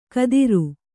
♪ kadiru